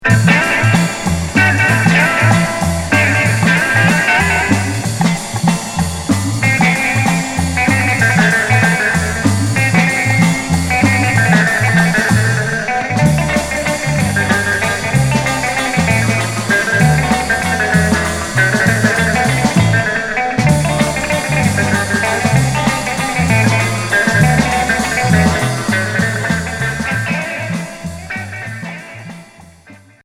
Instrumental
Rock instrumental